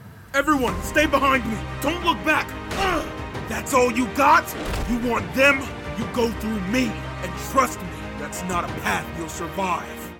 Teen
Young Adult
Character Voice